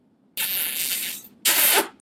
kiss.ogg.mp3